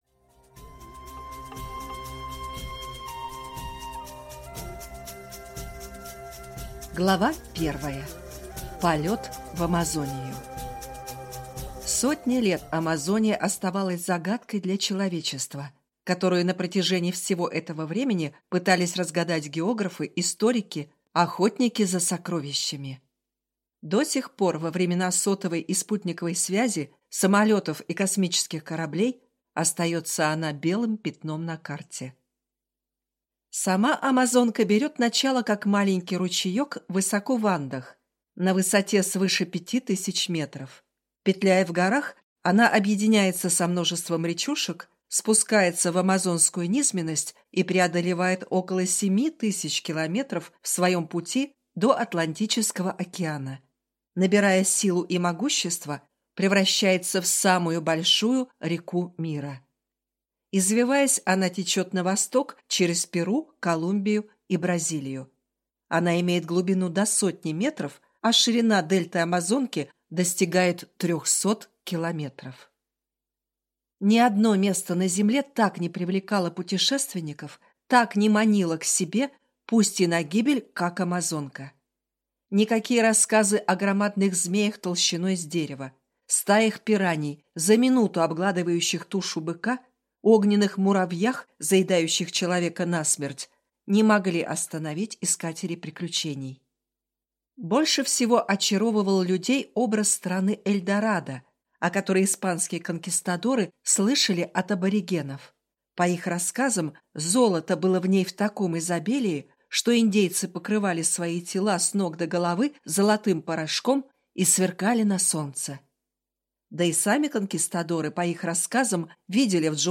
Аудиокнига Шёпот джунглей, или Тропою долгожителей | Библиотека аудиокниг
Прослушать и бесплатно скачать фрагмент аудиокниги